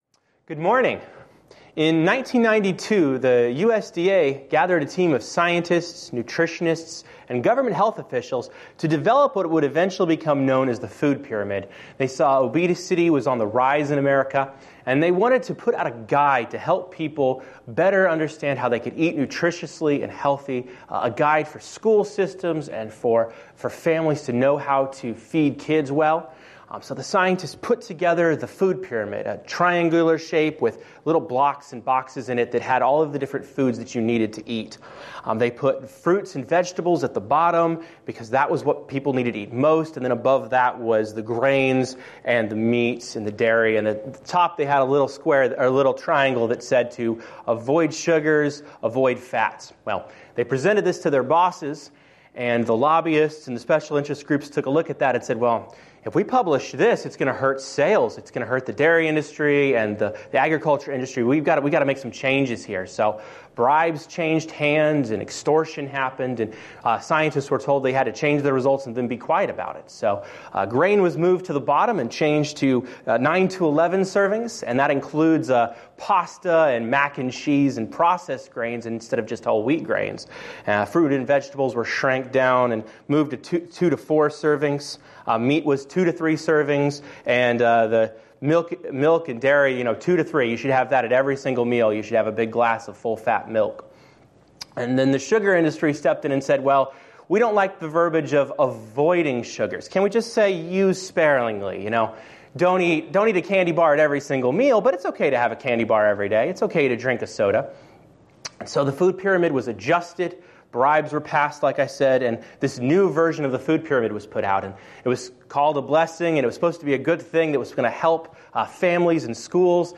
“God Is Our Fortress” Preacher